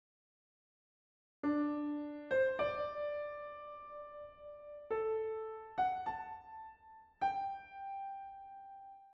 the creative artist (oboe):